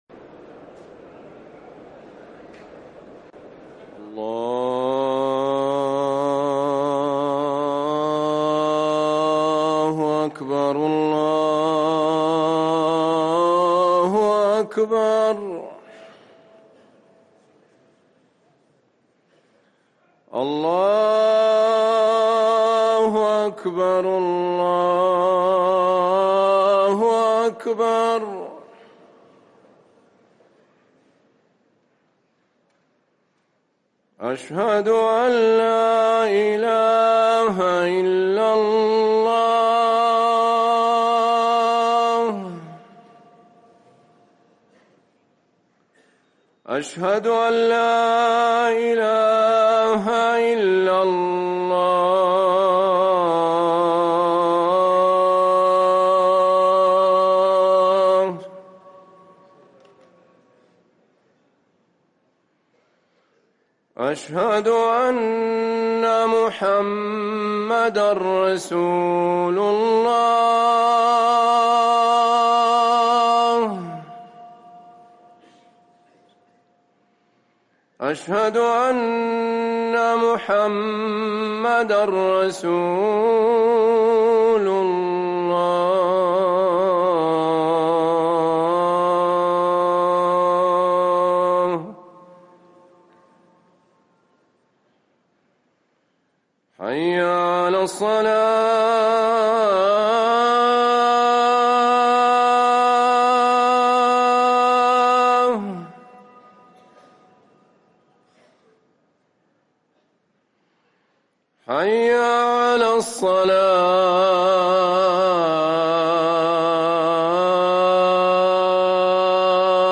اذان الفجر
ركن الأذان